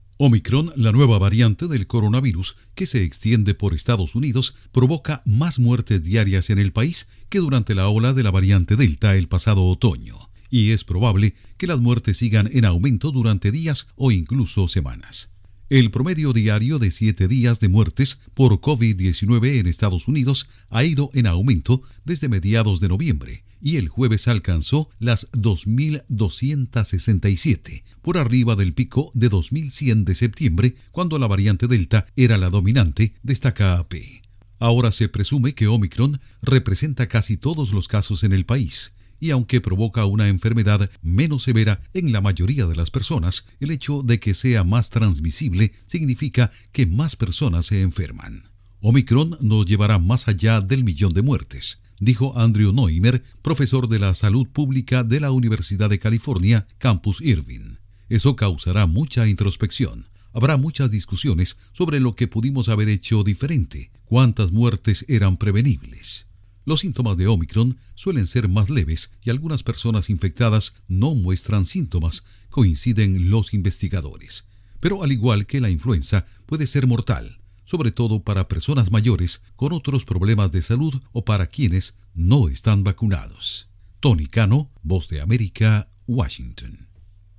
AudioNoticias
Informa desde la Voz de América en Washington